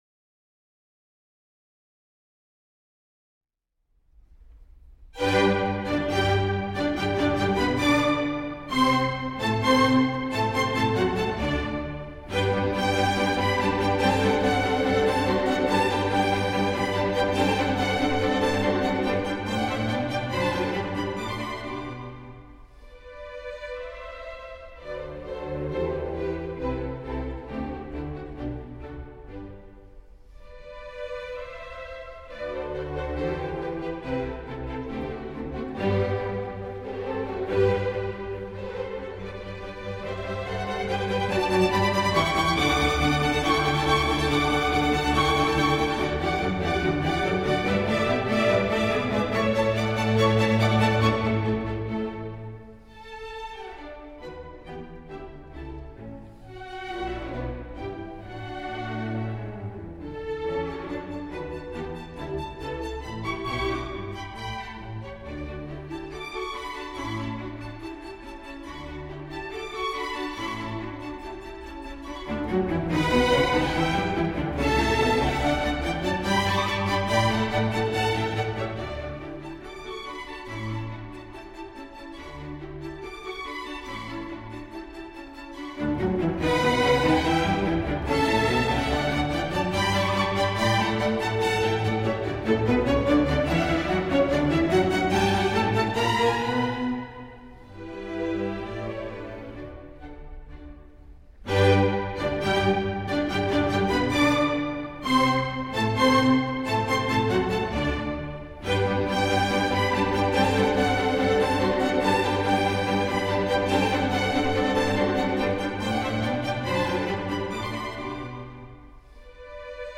Allegro K. 525 piano